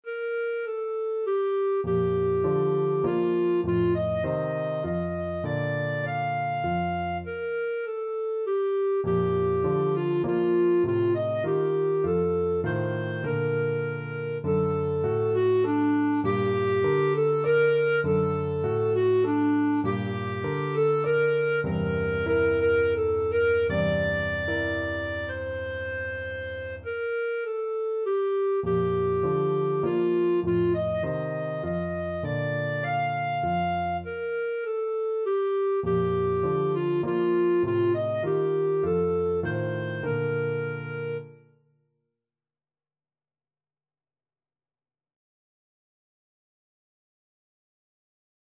Clarinet
Bb major (Sounding Pitch) C major (Clarinet in Bb) (View more Bb major Music for Clarinet )
3/4 (View more 3/4 Music)
Allegro moderato (View more music marked Allegro)
Classical (View more Classical Clarinet Music)
chopin_wish_CL.mp3